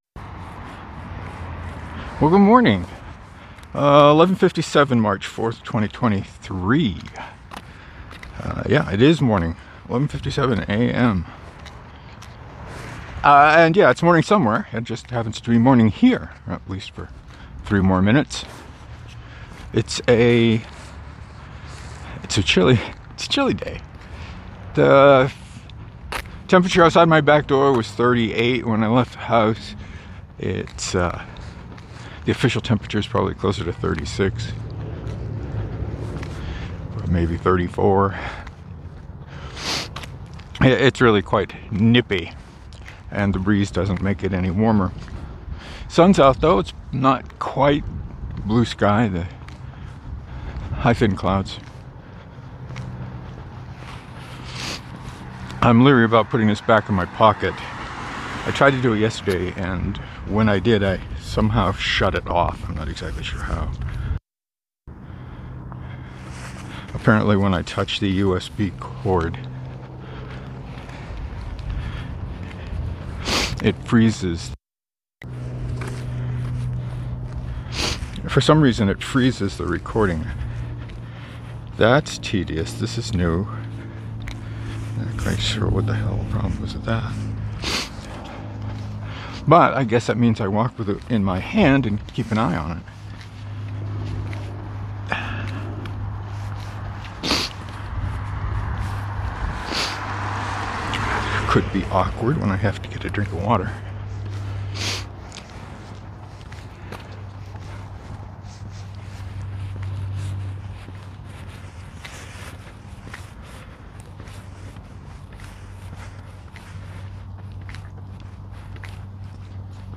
The dongle I use to connect the mic to the phone seems to be a little shaky. If I bumped it the wrong way, the recording stopped.